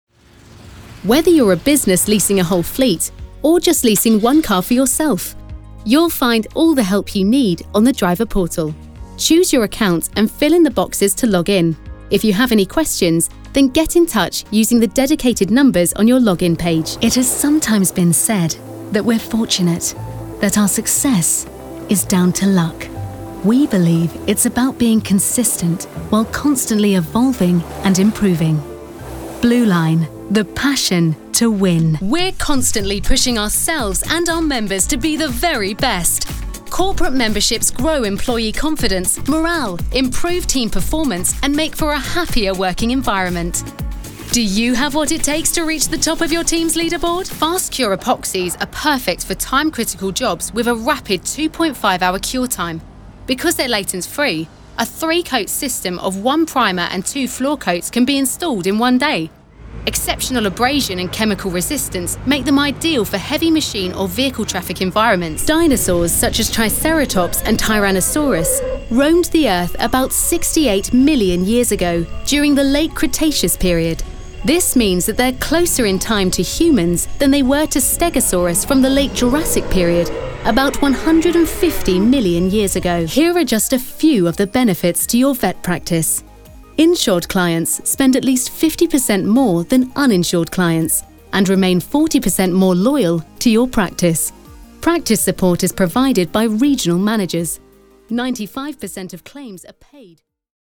Inglés (Británico)
Comercial, Cool, Versátil, Cálida
Corporativo